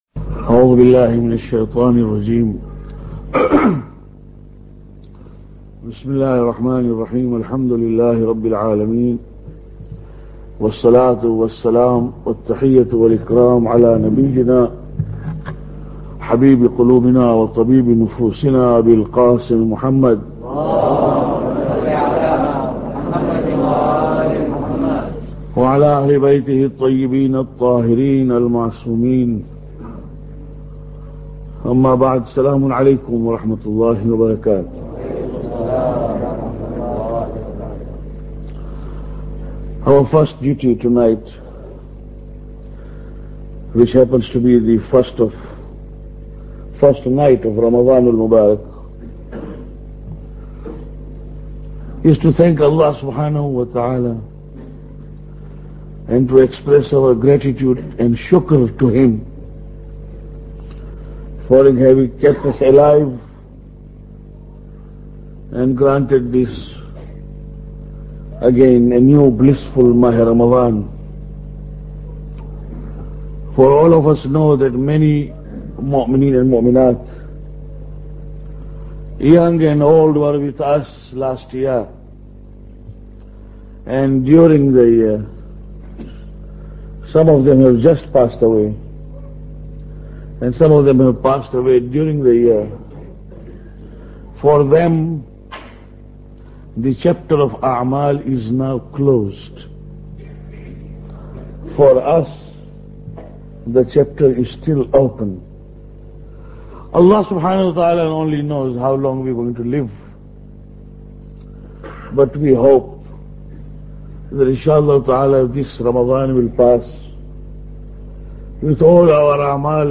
Lecture 1